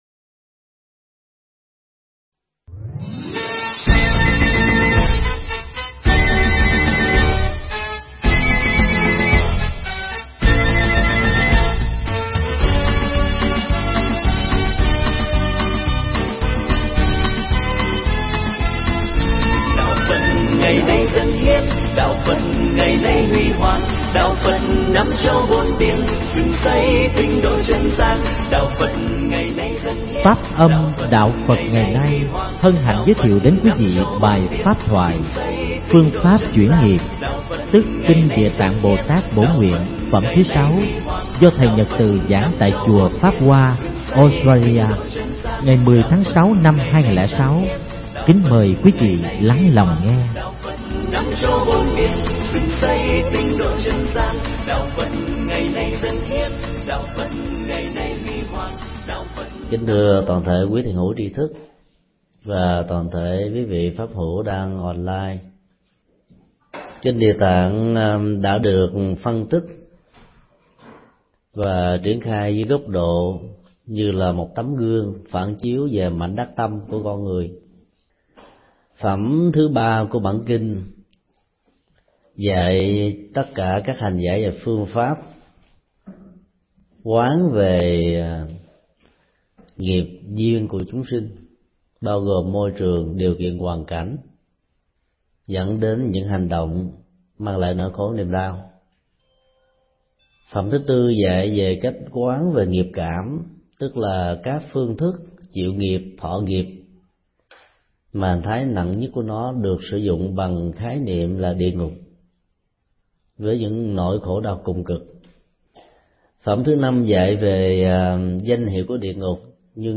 Tải mp3 Bài giảng Phương pháp chuyển nghiệp do thầy Thích Nhật Từ giảng tại chùa Pháp Hoa - Autralia ngày 10 tháng 6 năm 2006